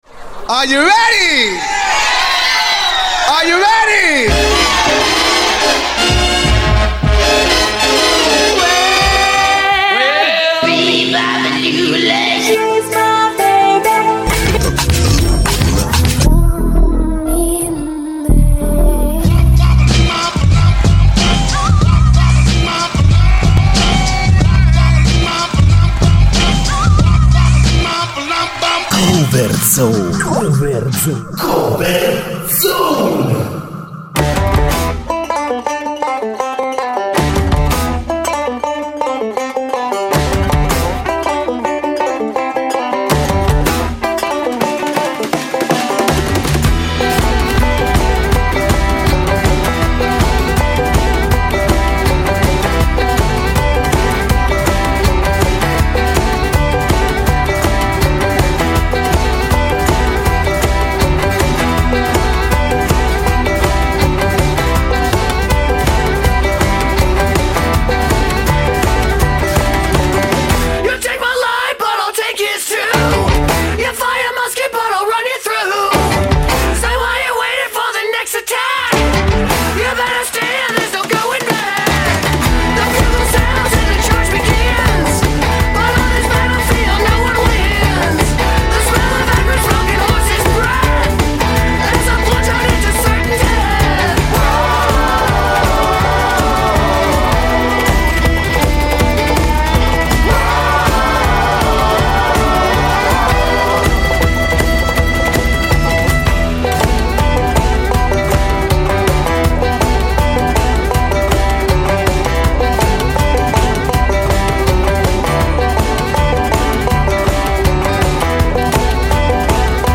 bluegrass da osteria